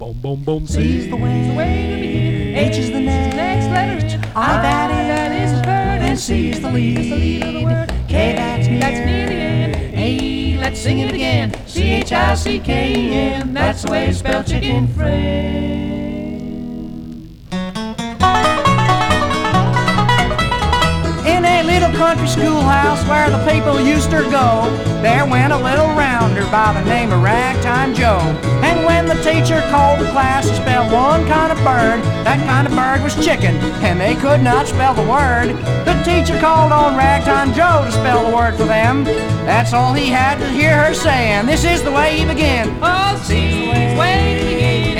Country, Bluegrass　USA　12inchレコード　33rpm　Mono